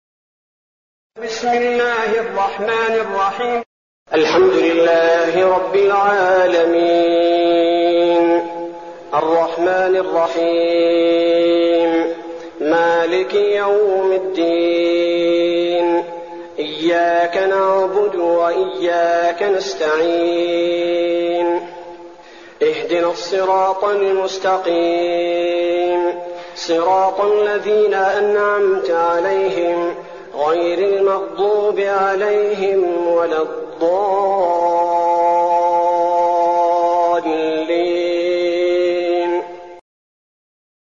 المكان: المسجد النبوي الشيخ: فضيلة الشيخ عبدالباري الثبيتي فضيلة الشيخ عبدالباري الثبيتي الفاتحة